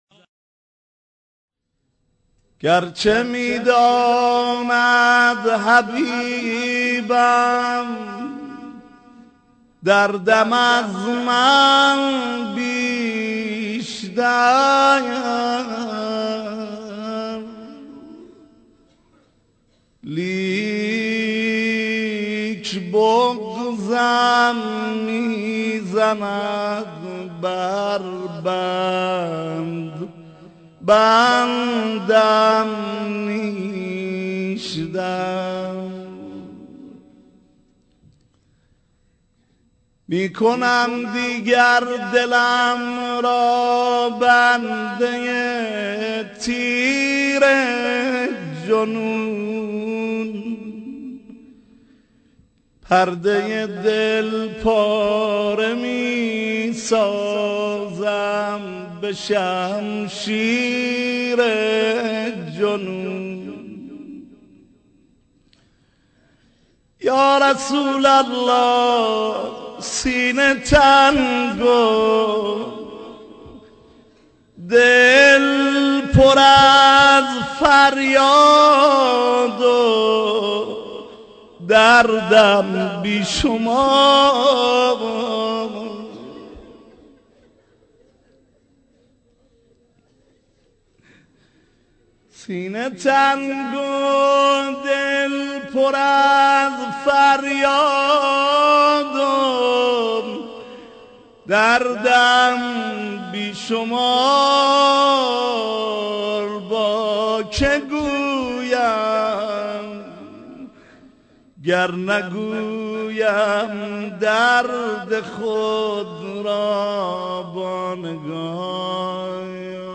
روضه ي امام حسن مجتبي(ع)